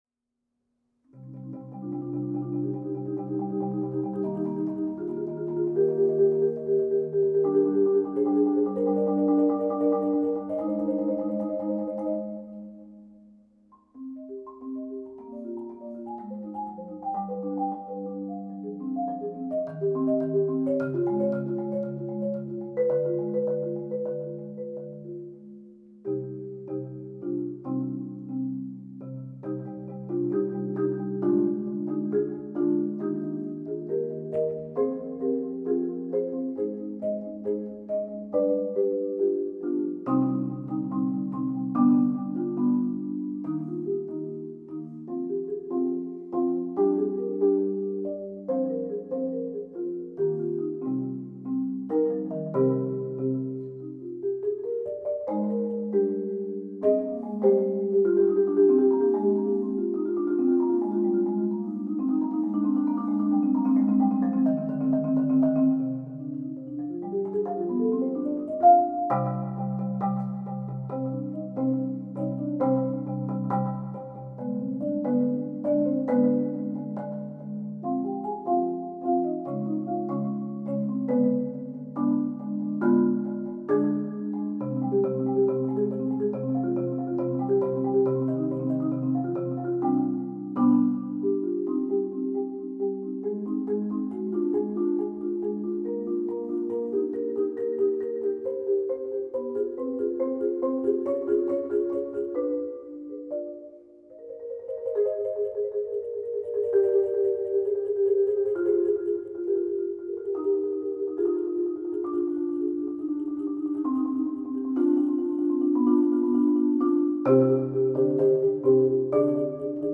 Genre: Marimba (4-mallet)
Marimba (4.3-octave)